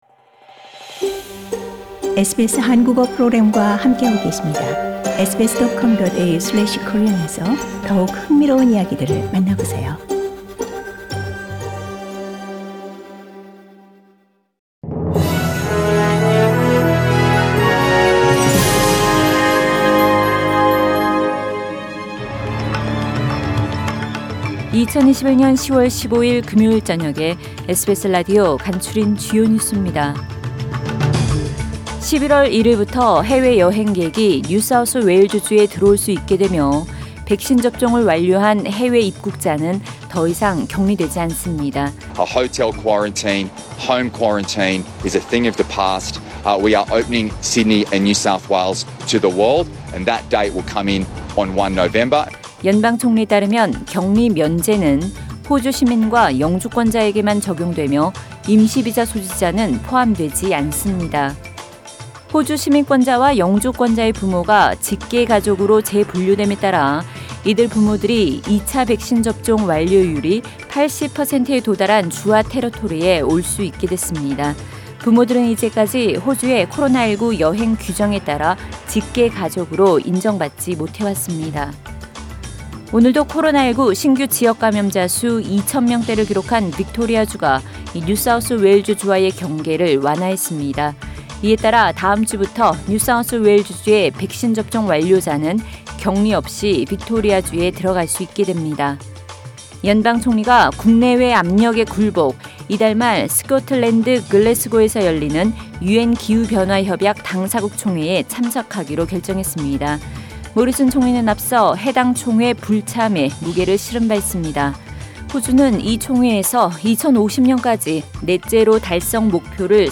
SBS News Outlines…2021년 10월 15일 저녁 주요 뉴스
2021년 10월 15일 금요일 저녁의 SBS 뉴스 아우트라인입니다.